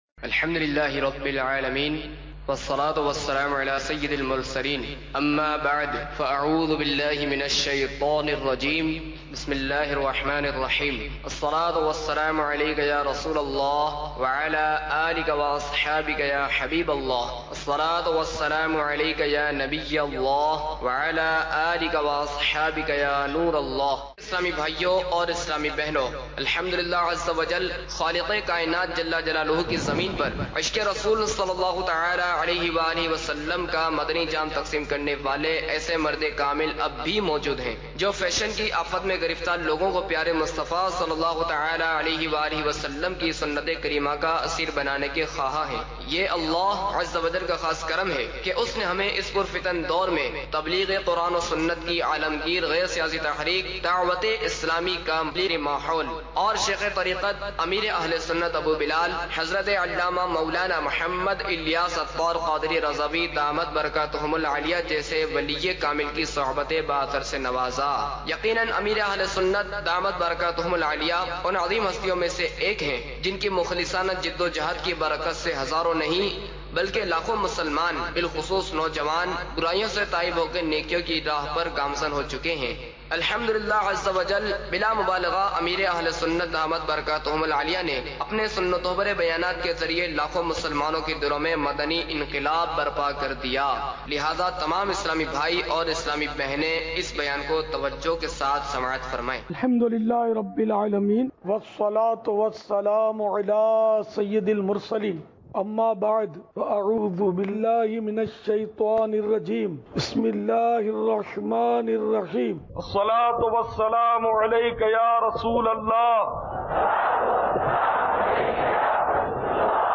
Audio Bayan – Aag Se Bacho Aur Bachao
آڈیو بیان